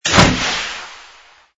ui_equip_mount03.wav